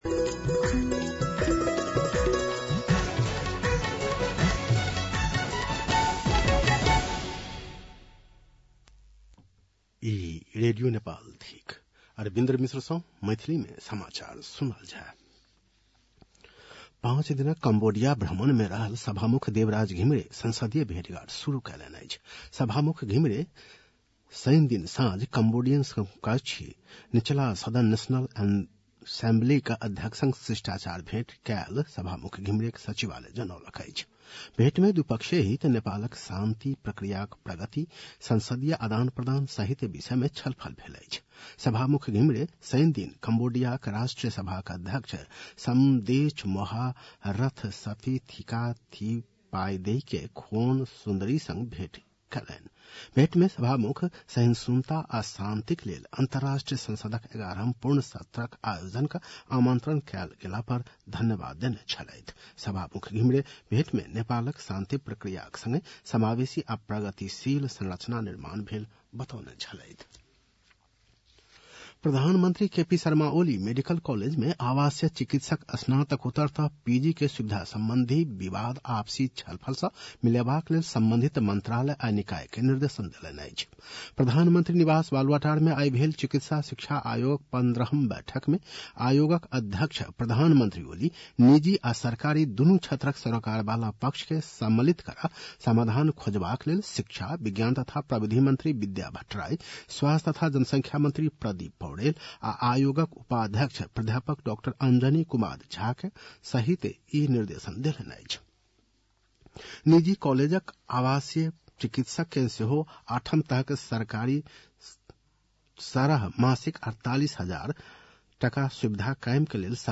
मैथिली भाषामा समाचार : १० मंसिर , २०८१
Maithali-News-8-9-1.mp3